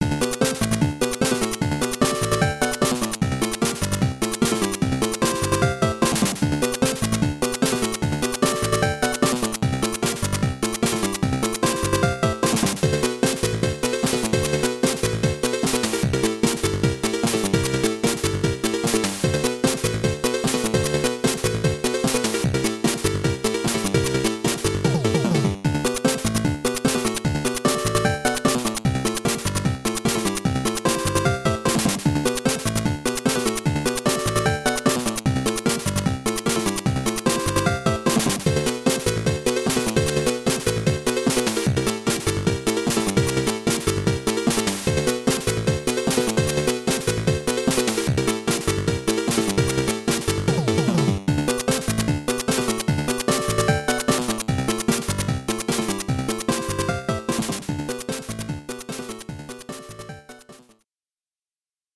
This music was recorded using the game's sound test.